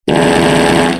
rapid fart
rapid-fart.mp3